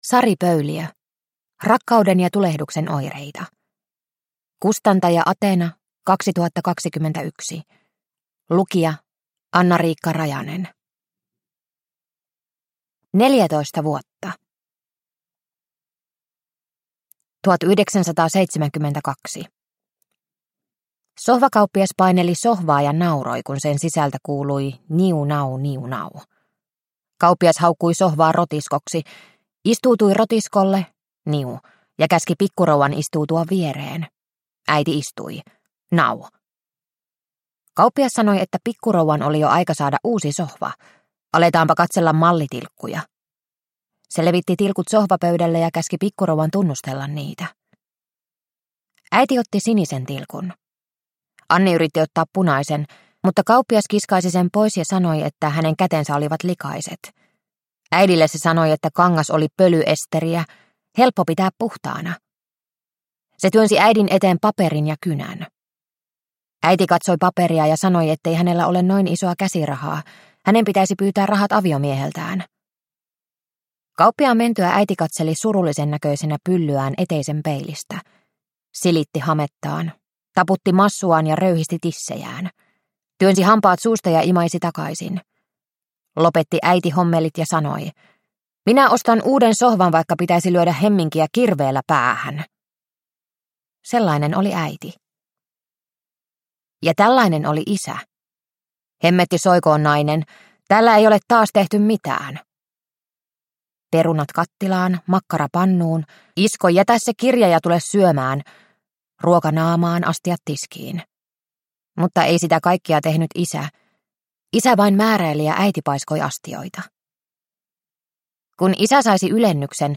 Rakkauden ja tulehduksen oireita – Ljudbok – Laddas ner